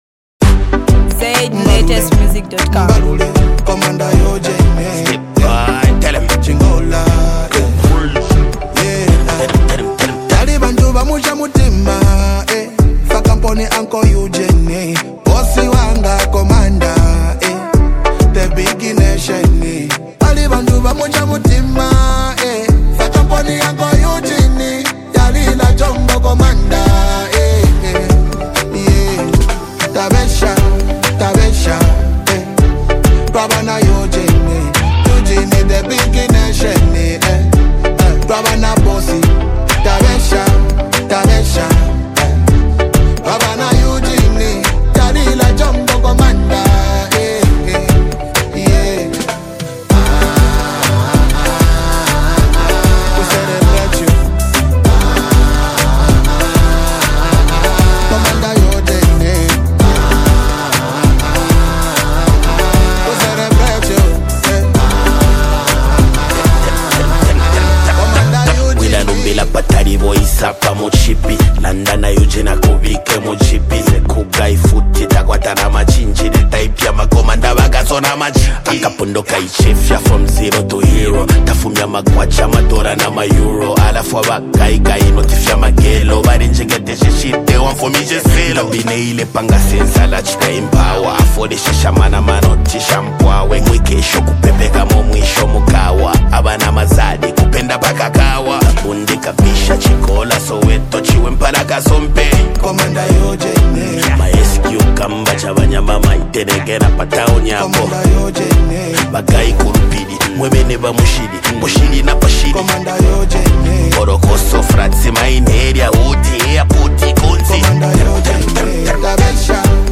blending catchy melodies with meaningful songwriting.
Genre: Afro-Beats